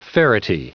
Prononciation du mot ferrety en anglais (fichier audio)
Prononciation du mot : ferrety